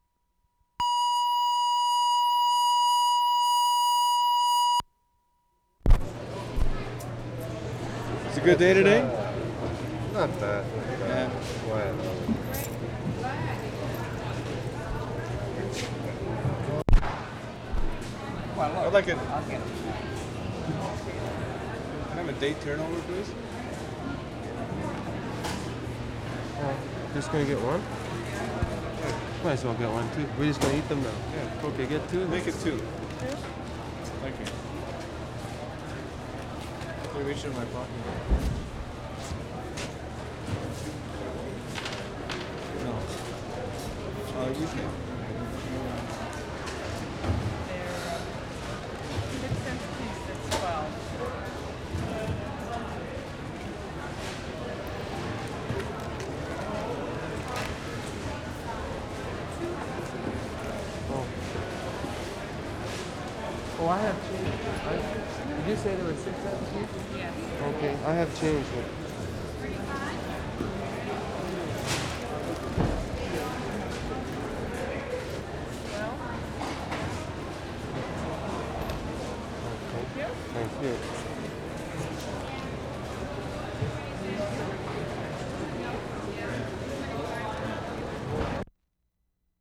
MENNONITE FARMER'S MARKET, continued 1'40"
1. Recordists buying something for their sweet tooth.